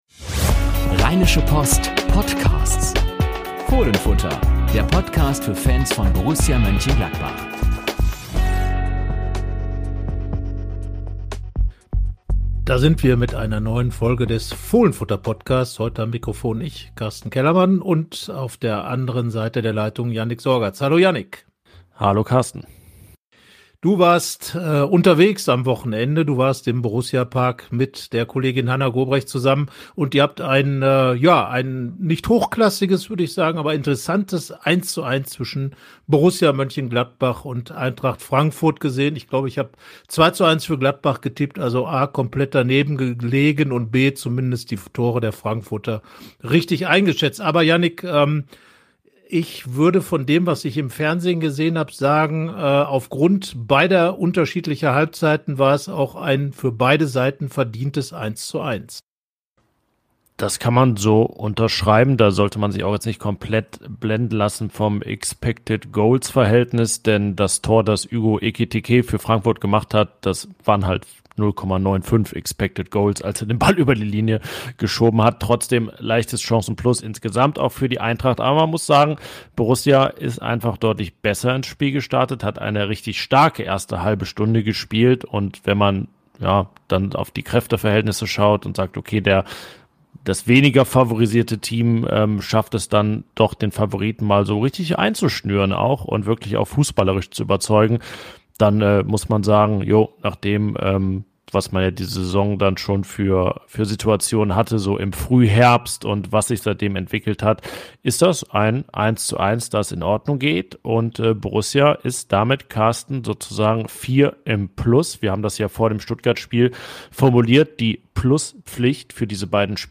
Jede Woche das Neueste zu Borussia – Diskussionen, Analysen und Interviews rund um den Verein.